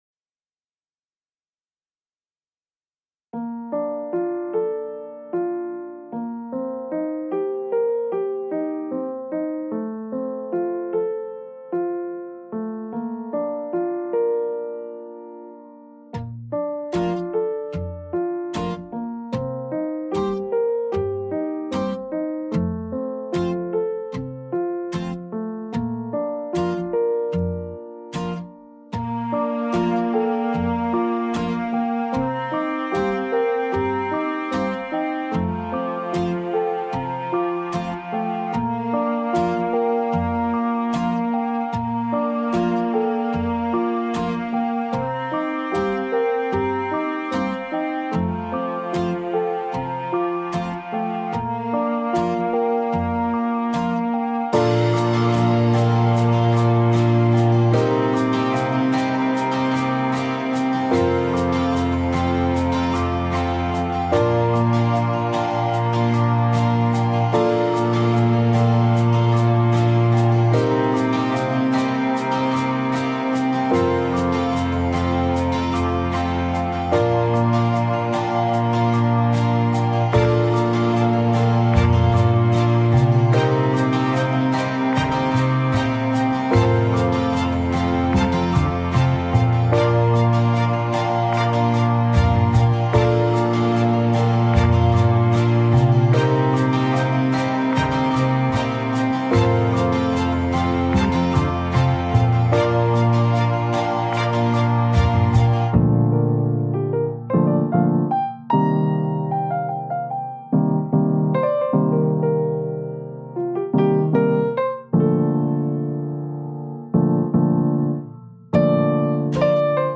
CINEMATIC VLOG NON COPYRIGHT MUSIC ROMANTI